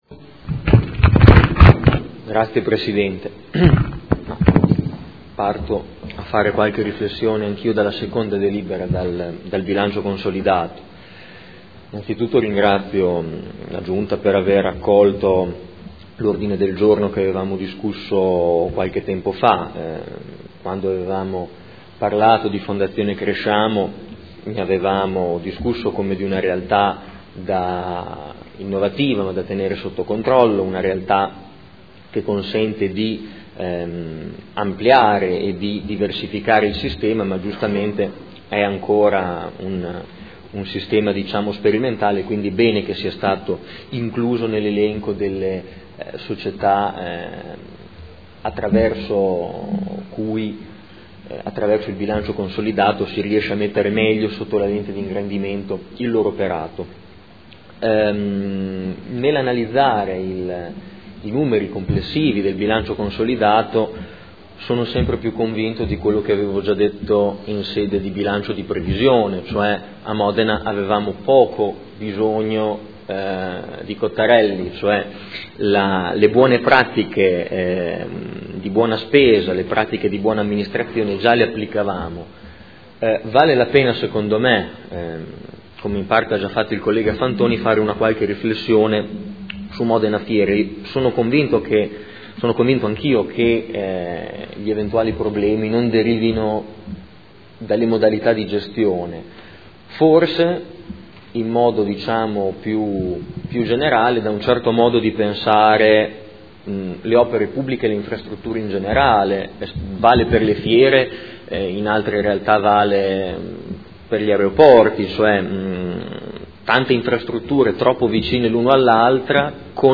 Seduta del 21/09/2015.
Dibattito